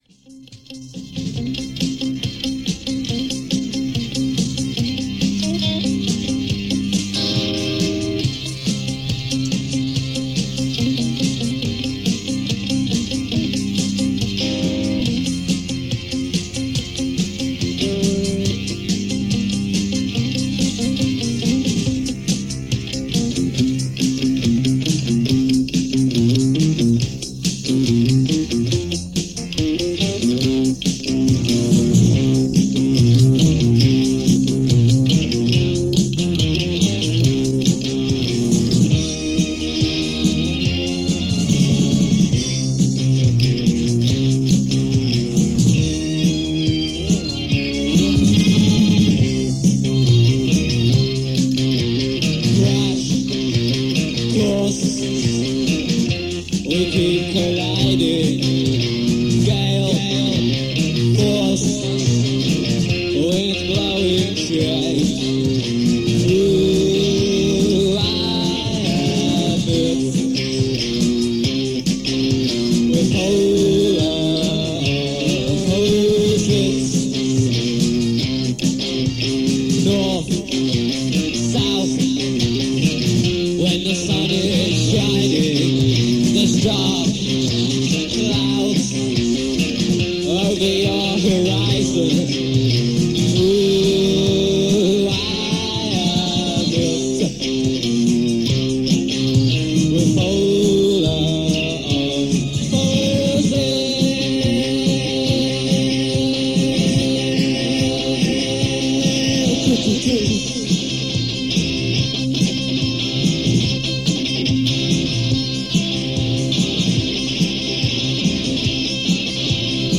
They wrote catchy songs enveloped in keyboards.